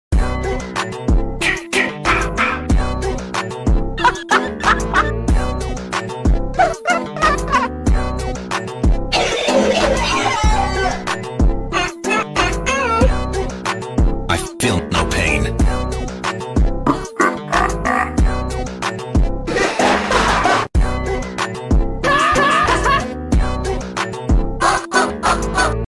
Forsaken laughs meme
tiktok funny sound hahaha